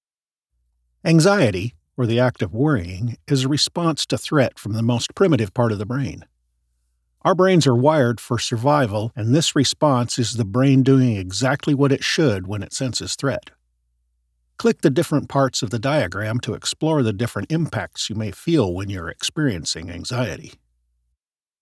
Adult (30-50) | Older Sound (50+)